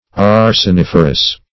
Search Result for " arseniferous" : The Collaborative International Dictionary of English v.0.48: Arseniferous \Ar`sen*if"er*ous\, a. [Arsenic + -ferous.]